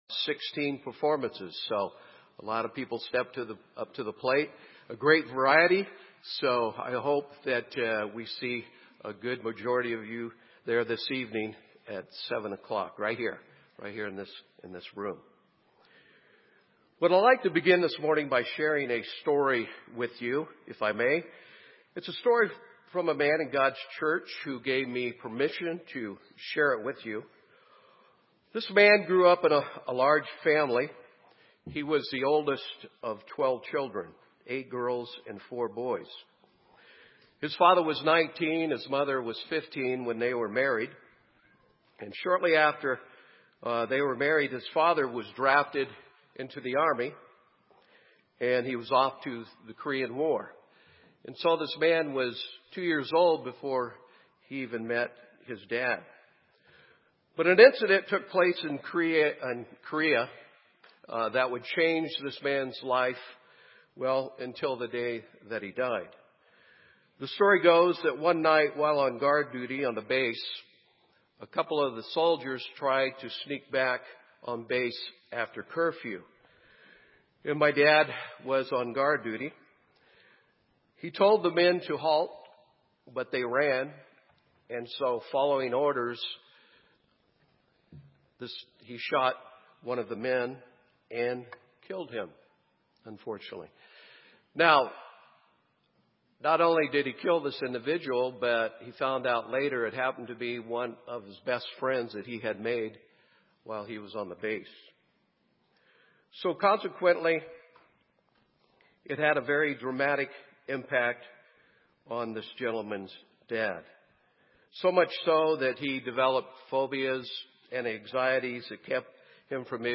This sermon was given at the Bend, Oregon 2016 Feast site.